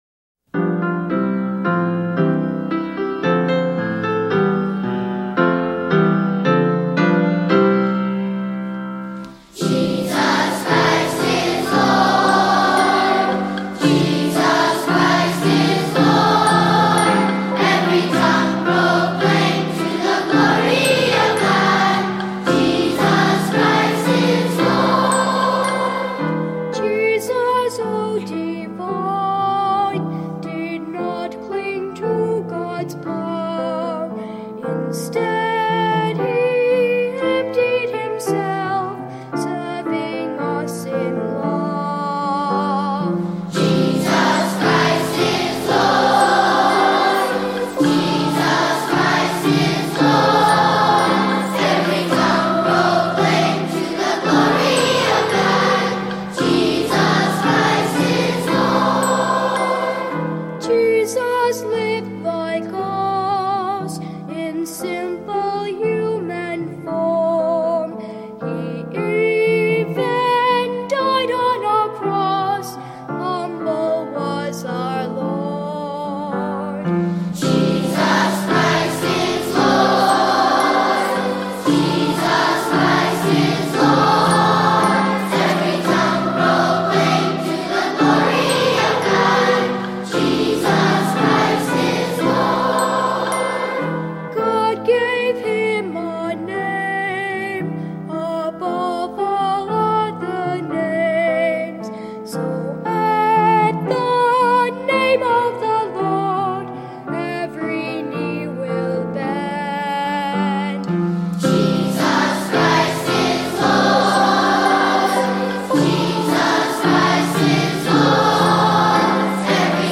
Voicing: Unison or Two-Part Choir